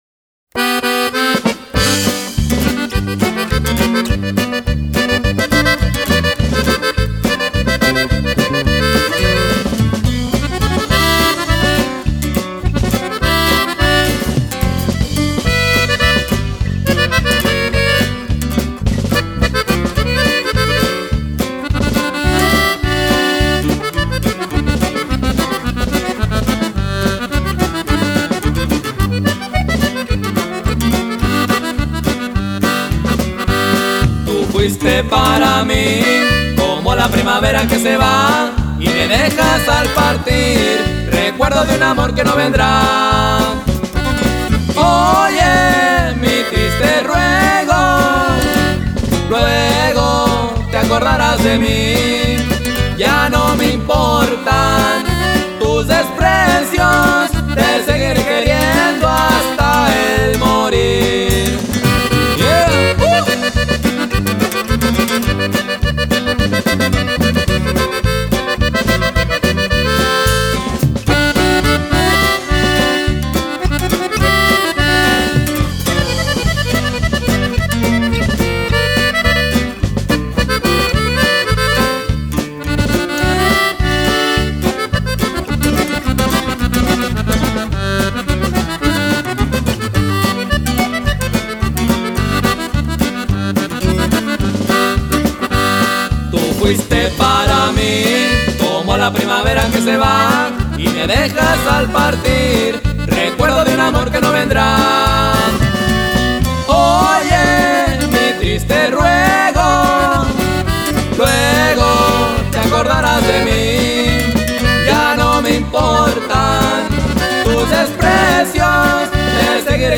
El duo mas reciente del momento
musica norteña